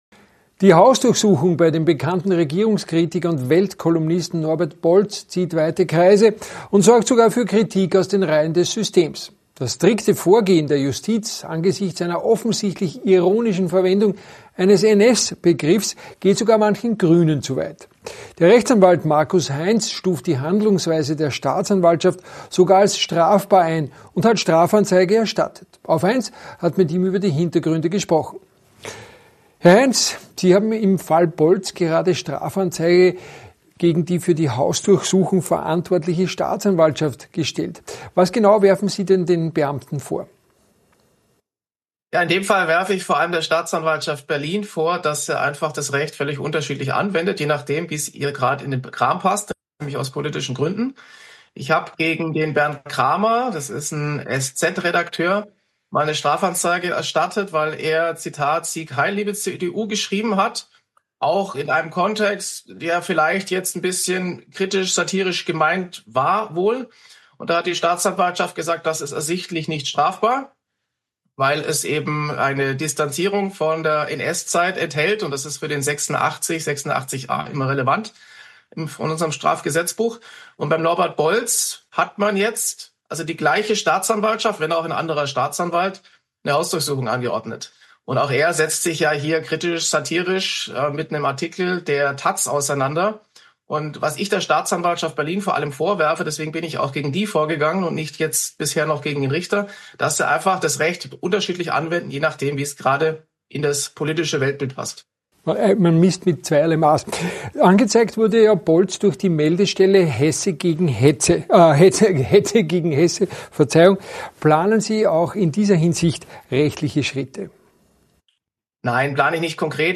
exklusiven Interview mit AUF1.